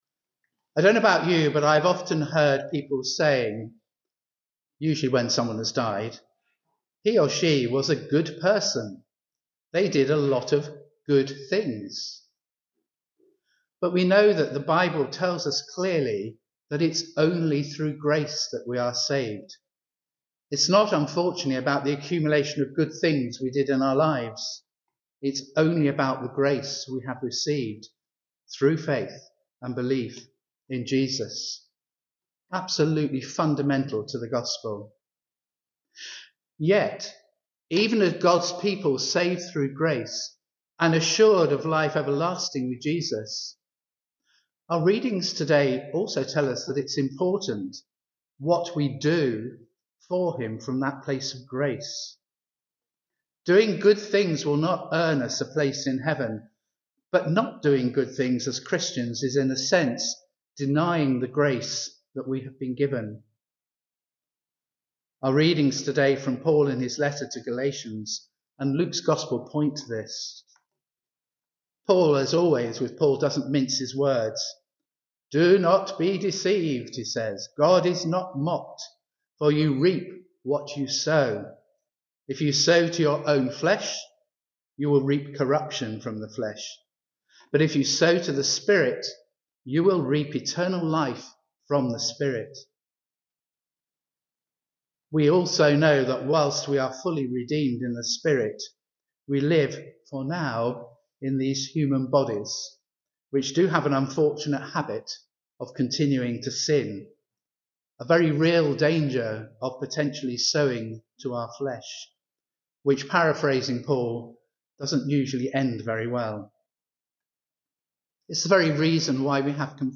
Recent Sermons and Other Audio Recordings -